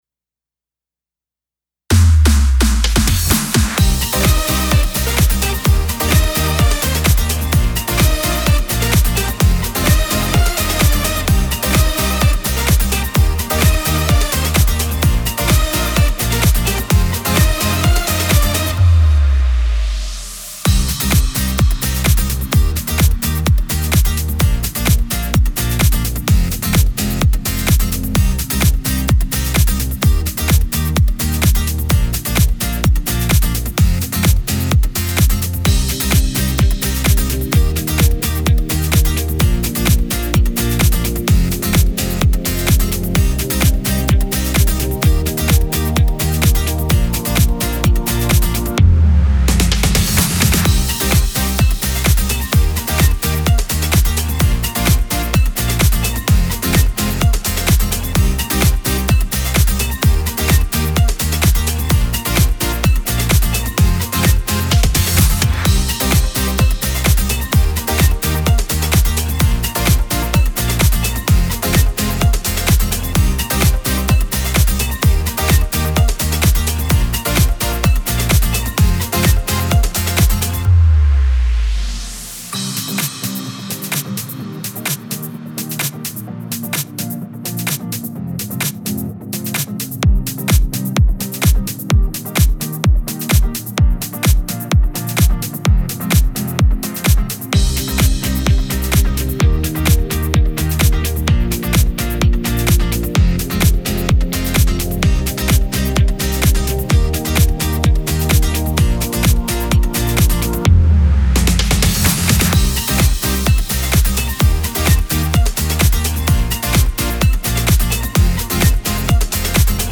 МУЗЫКА